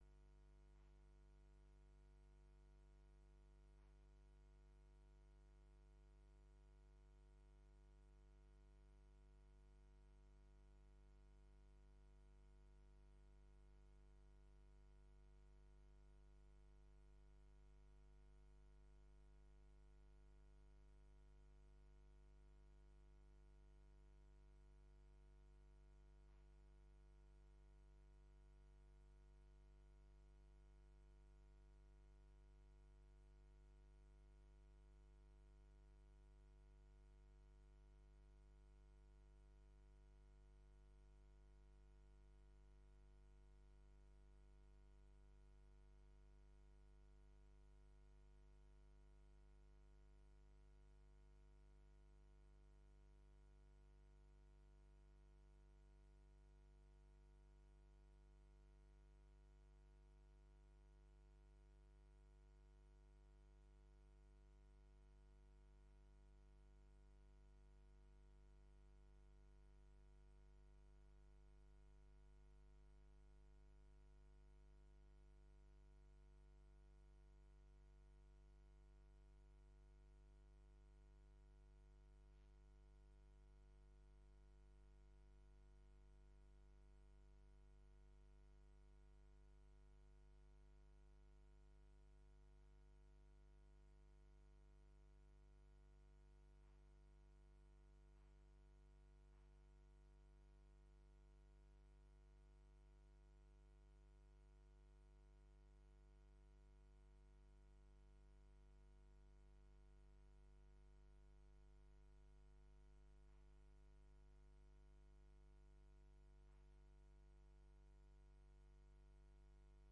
Vergadering van de commissie Samenlevingszaken op maandag 20 juni 2022, om 21.00 uur, fysiek in kamer 62/63 van het gemeentehuis.